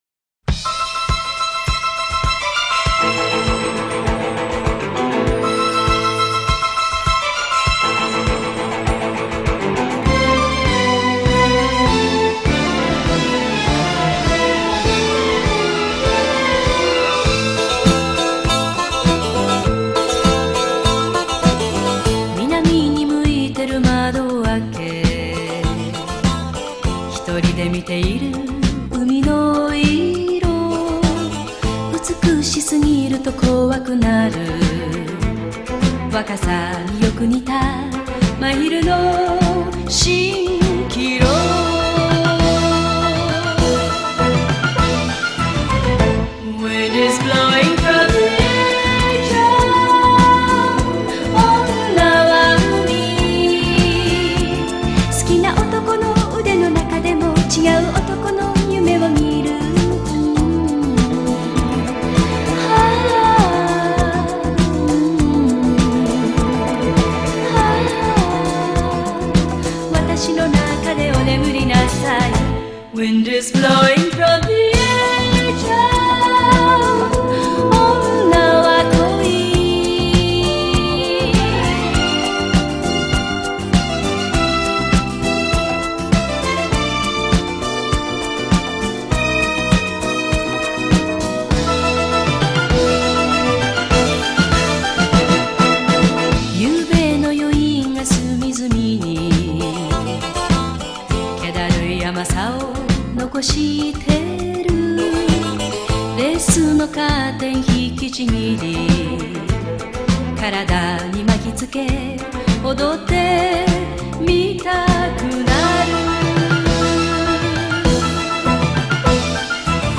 Японская песня